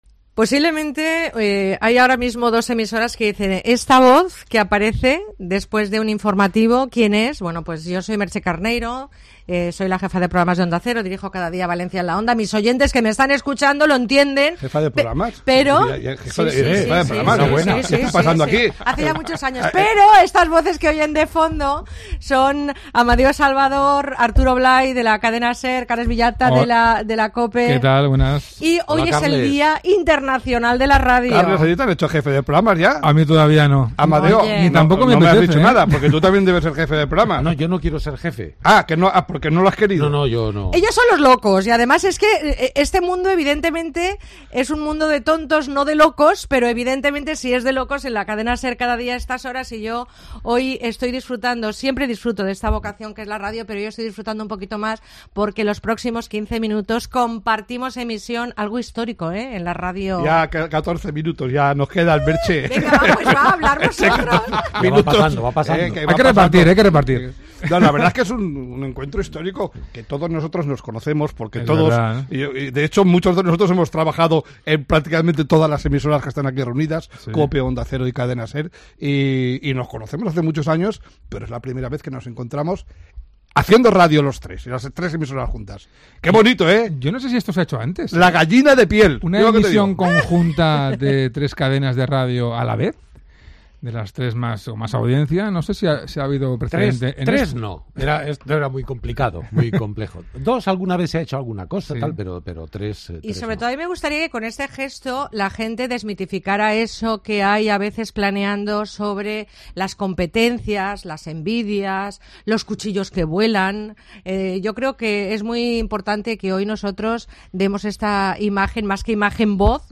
COPE, SER y ONDA CERA unidas durante unos maravillosos minutos de radio donde los conductores de los magazines han hecho un repaso a su experencia en radio y sus anécdotas a lo largo de las intesas jornadas en las ondas.